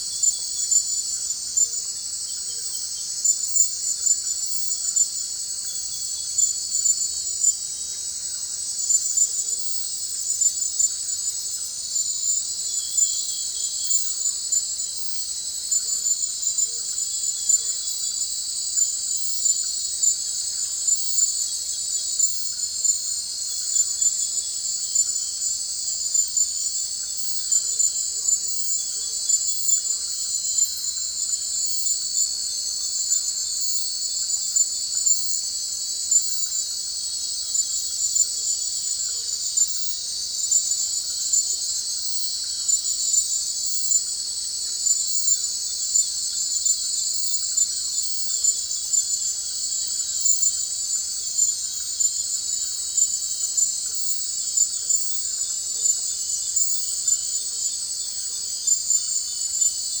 Finals-Data-Ultrasound